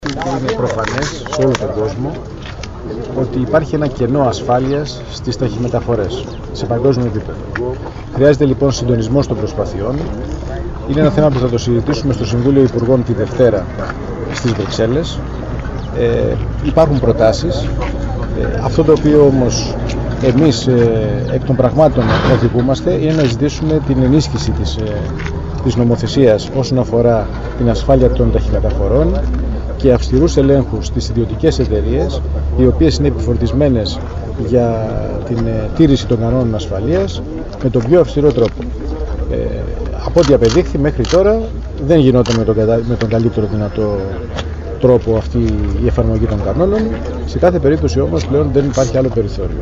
Για “κενό ασφάλειας στις ταχυμεταφορές σε παγκόσμιο επίπεδο” έκανε λόγο ο Υπουργός Προστασίας του Πολίτη, Χρήστος Παπουτσής, απαντώντας σε ερώτηση για τα πακέτα “βόμβες”.
Δηλώσεις Χρ. Παπουτσή για τις ταχυμεταφορές